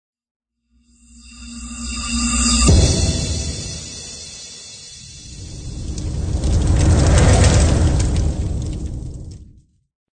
44.1 kHz 震撼大气片头音乐 全站素材均从网上搜集而来，仅限于学习交流。